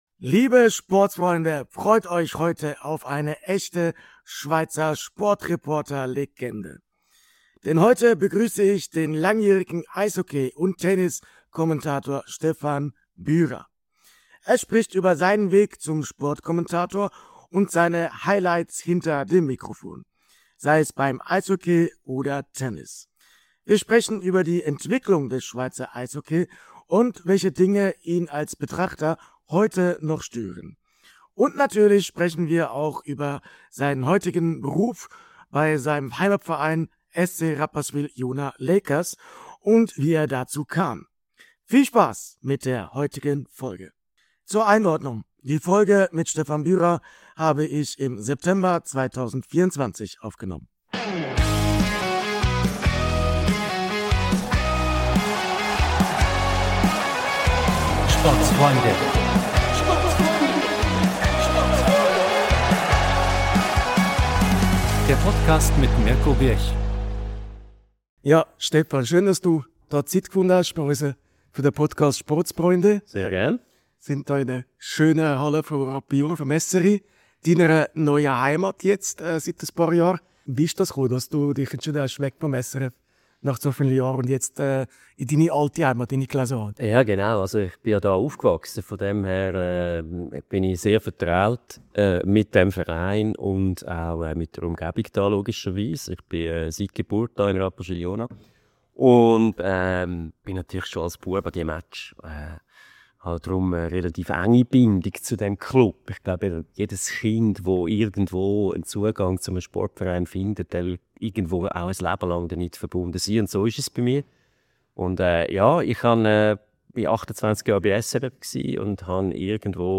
In der heutigen Spezialfolge auf Schweizerdeutsch begrüsse ich den langjährigen Eishockey- und Tenniskommentator Stefan Bürer. Er spricht über seinen Weg zum Sportkommentator und seine grössten Highlights am Mikrofon sei es beim Eishockey oder Tennis. Ausserdem beleuchten wir die Entwicklung des Schweizer Eishockeys und Stefan sagt, was ihn am Eishockey in der Schweiz stört.